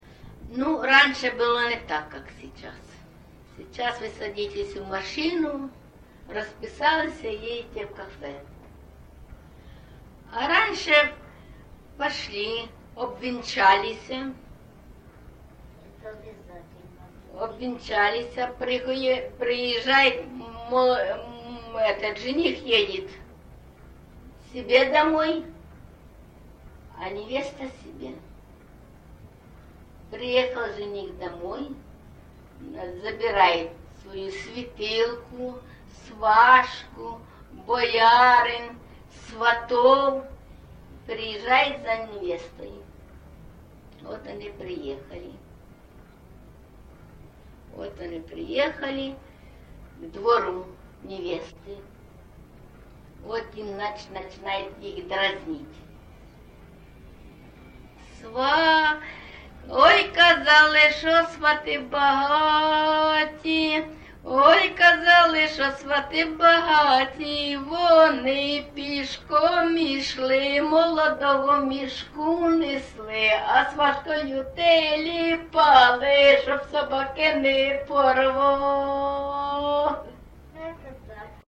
ЖанрВесільні
Місце записум. Маріуполь, Донецька обл., Україна, Північне Причорноморʼя